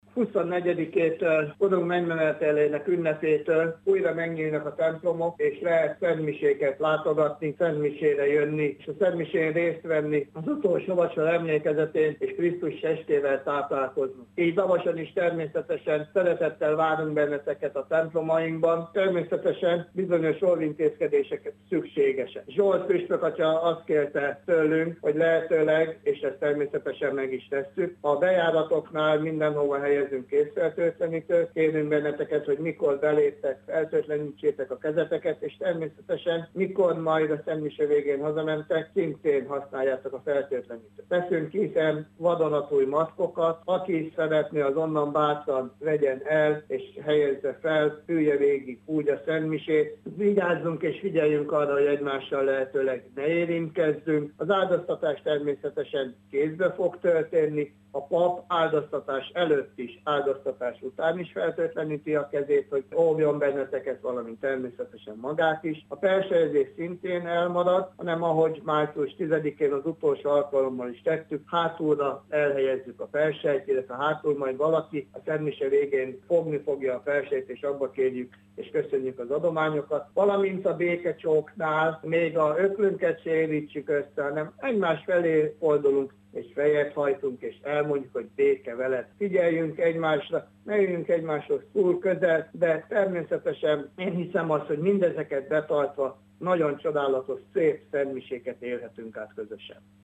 plébánost hallják.